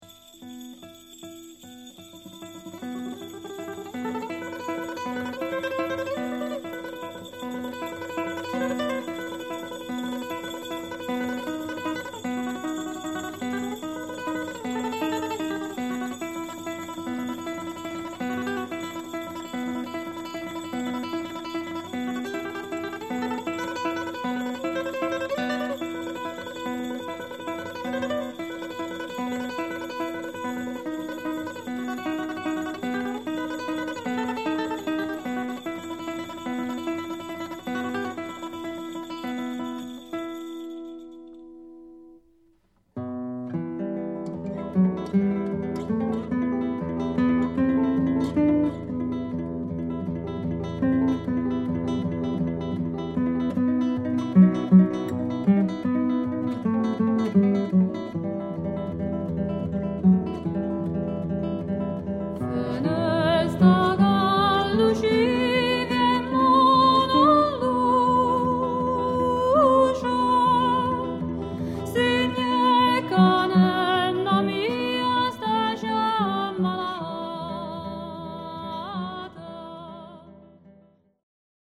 Chitarra e canzoni popolari del sud